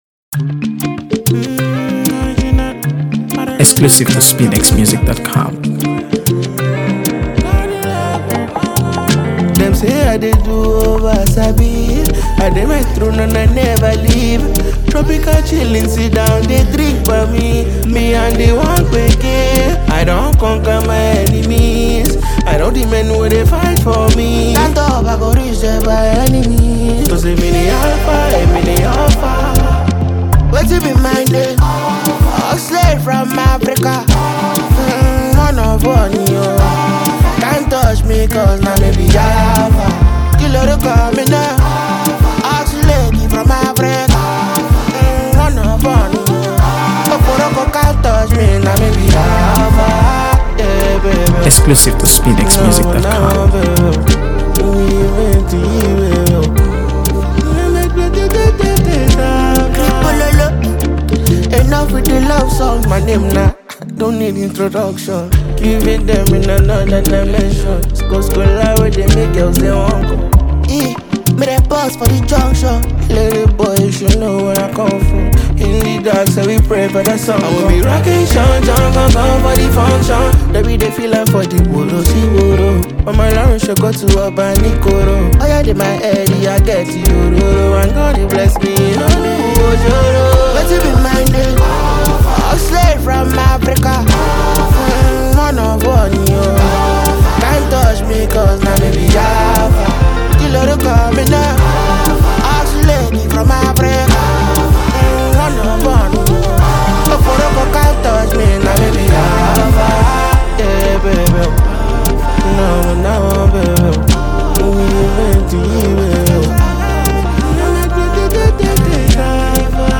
AfroBeats | AfroBeats songs
Nigerian multi-talented singer and songwriter